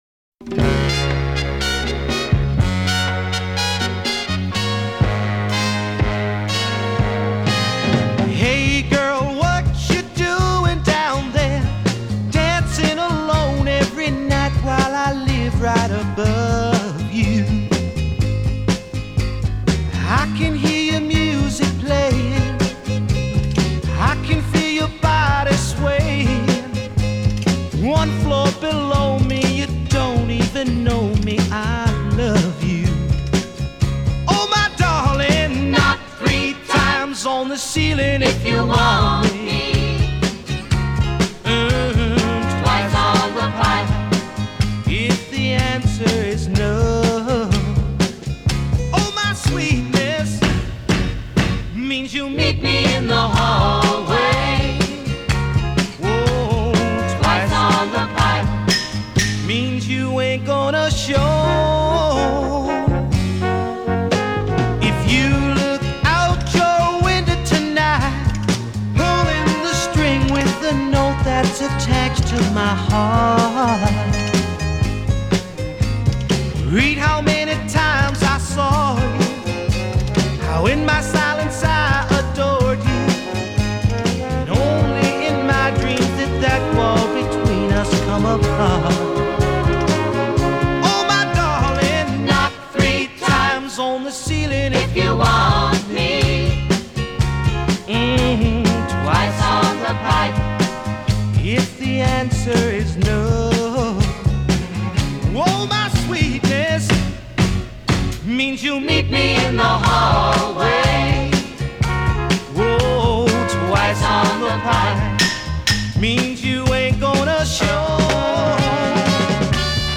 No te pierdas la recomendación musical que el comunicador ha preparado para ti este martes